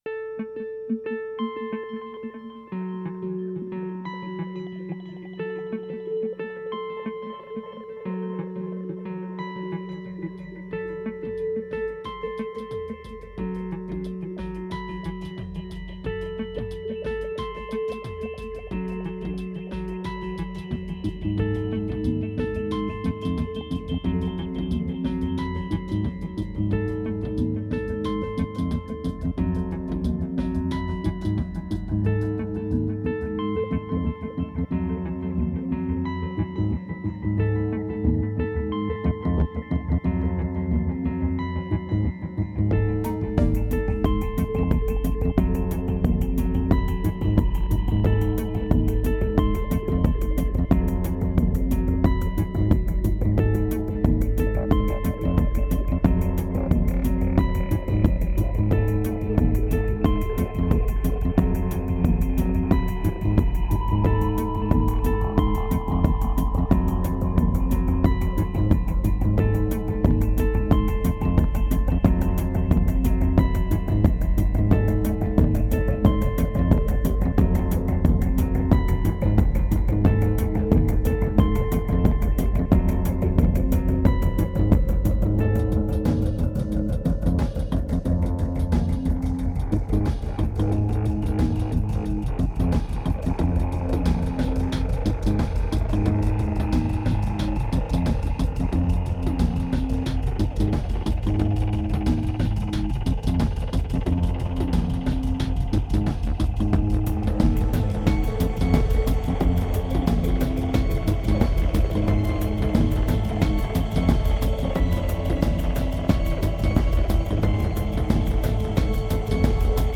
Pure remote desire, strong medication and raw electronica.
2461📈 - -36%🤔 - 90BPM🔊 - 2011-01-15📅 - -485🌟